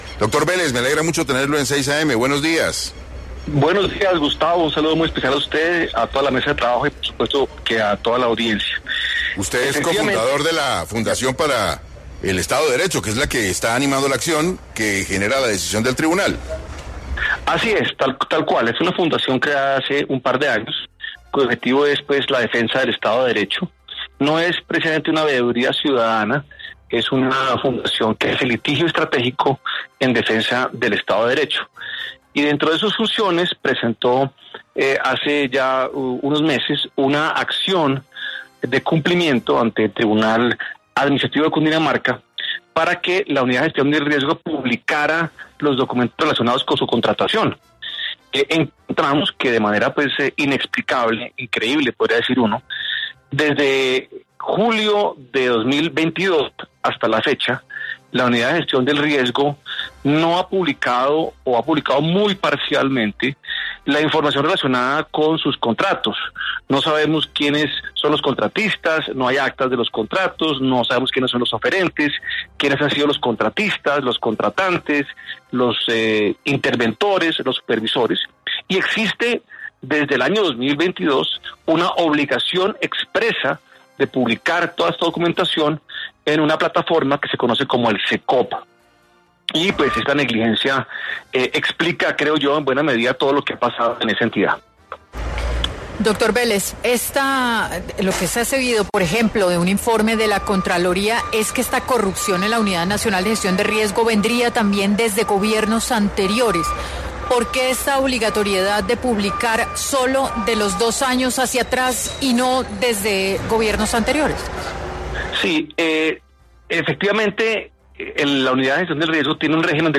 En 6AM Hoy por Hoy de Caracol Radio estuvo Luis Guillermo Vélez, exsuperintendente de Sociedades, para hablar sobre cómo impactará la publicación de la actividad contractual en el SECOP de la UNGRD y Fiduprevisora.